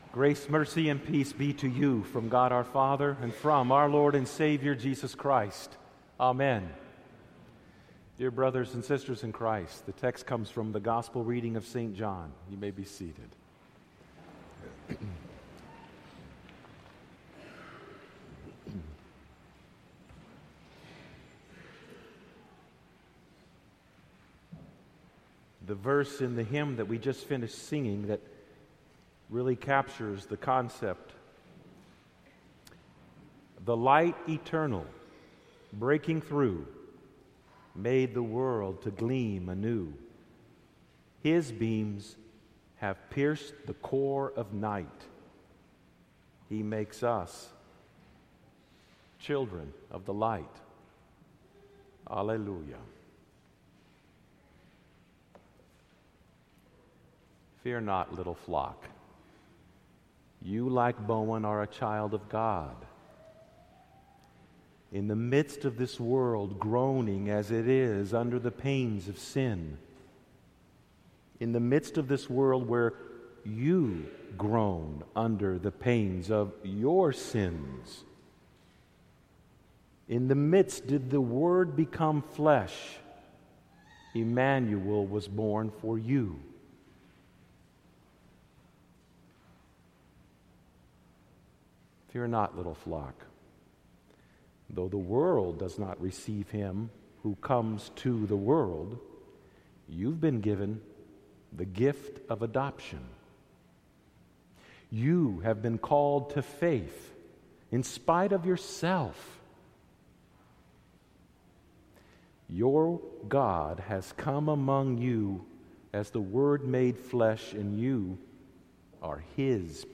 Series Christmas Day